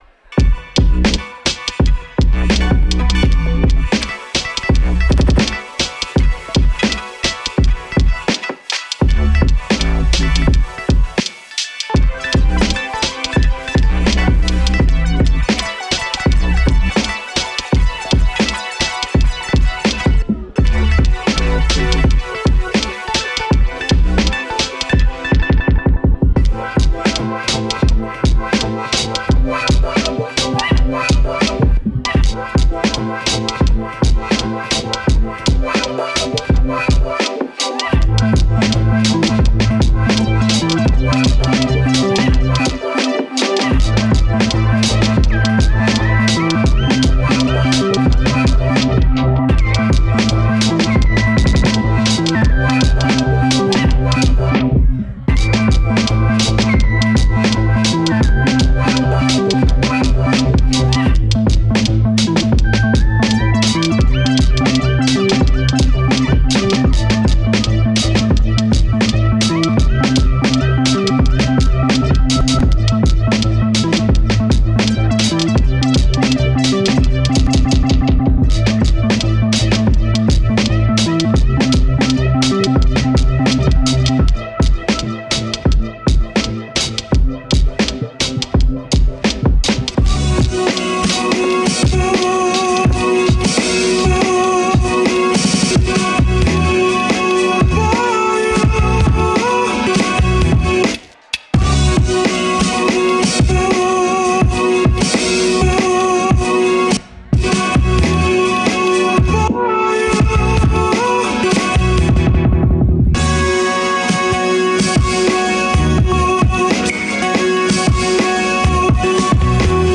Beats Hip Hop